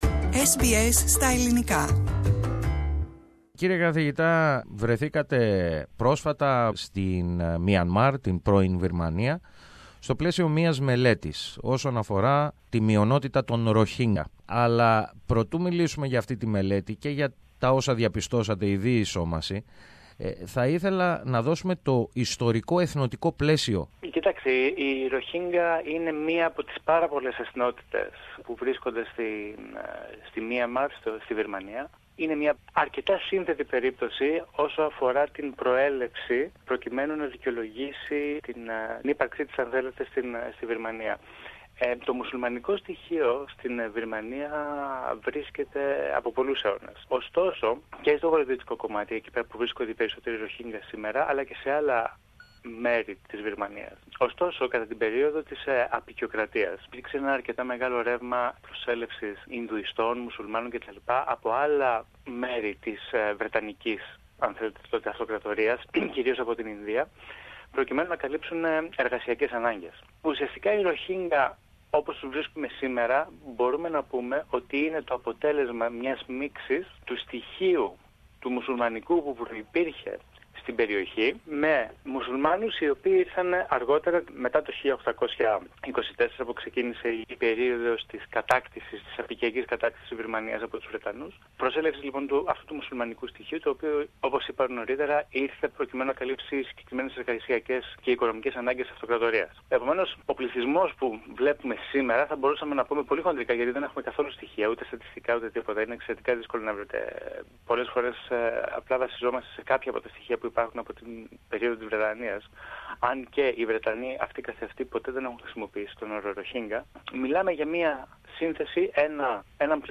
μίλησε στο ελληνικό πρόγραμμα της ραδιοφωνίας SBS